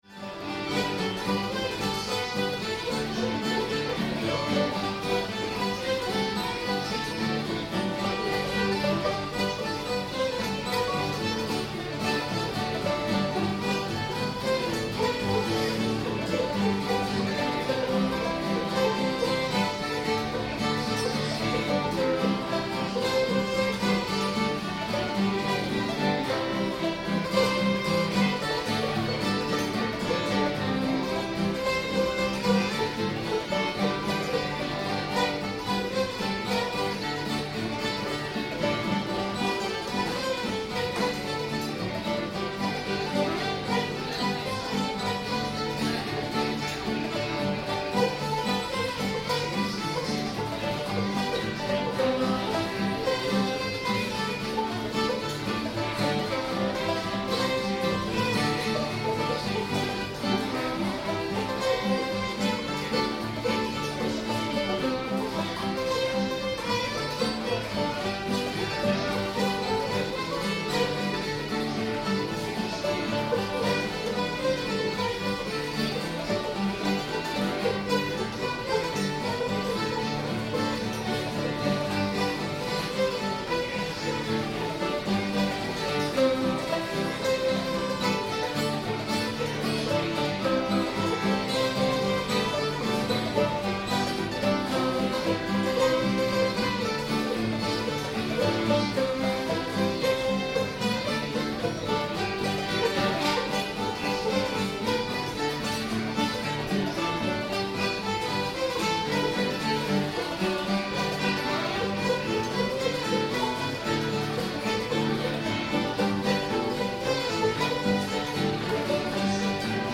nail that catfish to the tree [G]